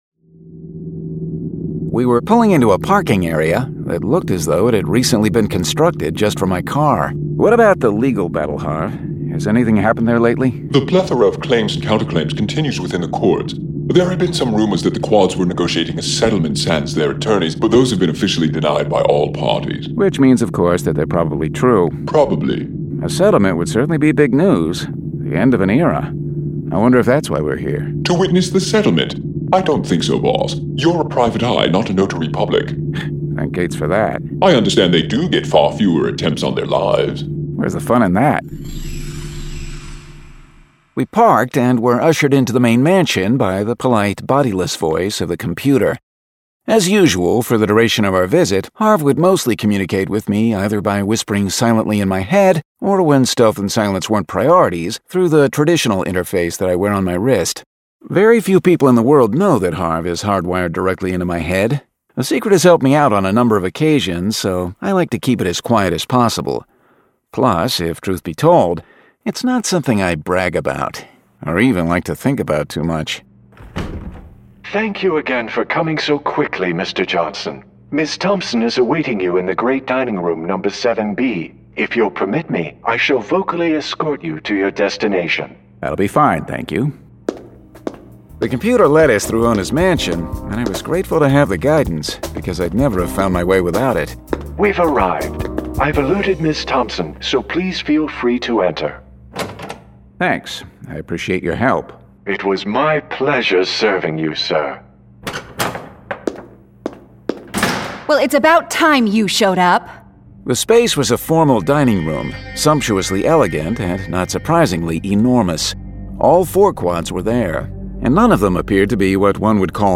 Full Cast. Cinematic Music. Sound Effects.
[Dramatized Adaptation]